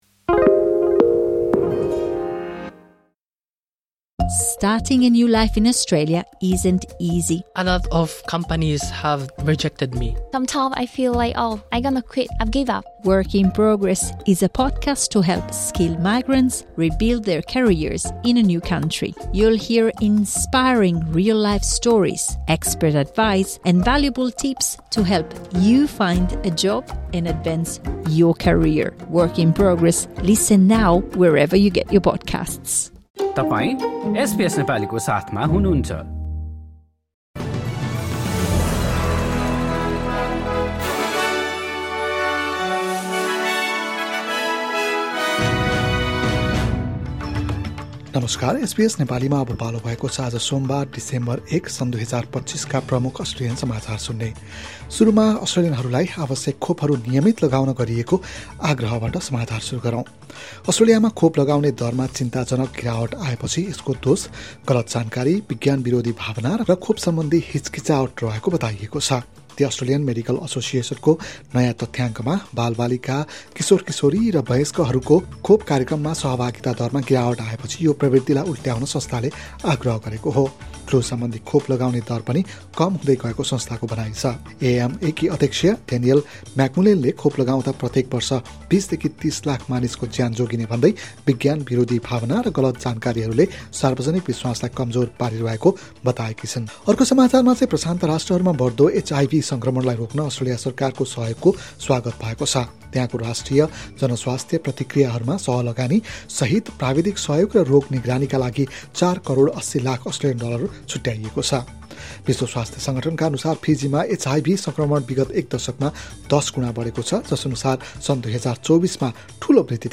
एसबीएस नेपाली प्रमुख अस्ट्रेलियन समाचार: सोमवार, १ डिसेम्बर २०२५